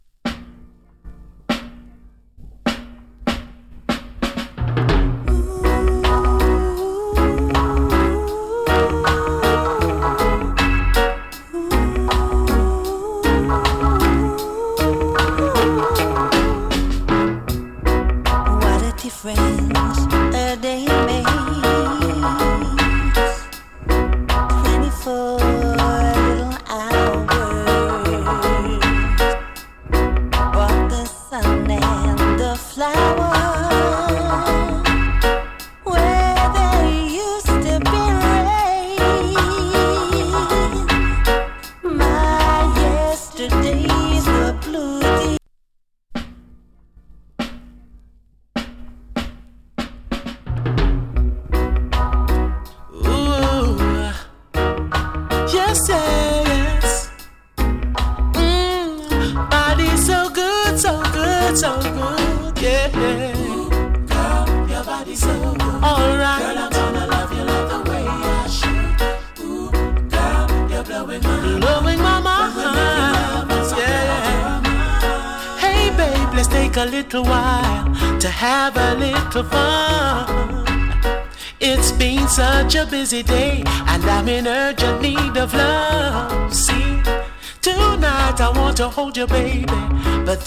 FEMALE VOCAL NICE ROCK STEADY !